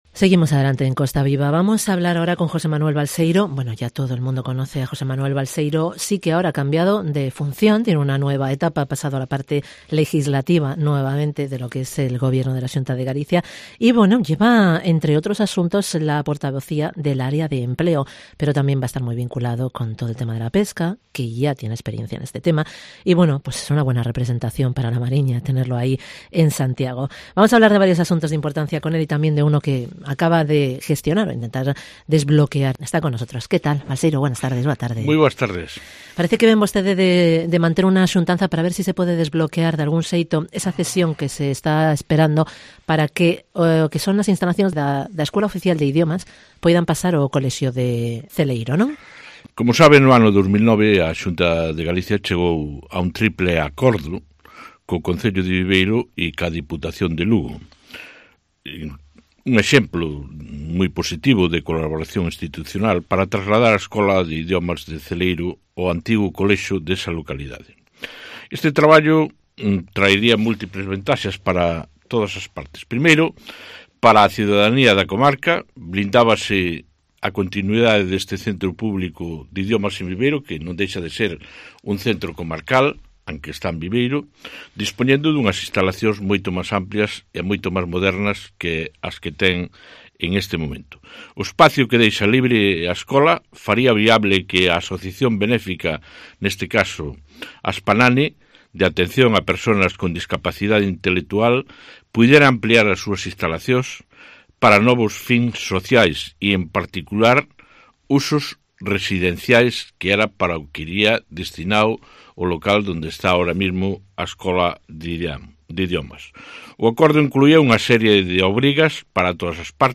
Entrevista JOSÉ MANUEL BALSEIRO, diputado del PP en el Parlamento Gallego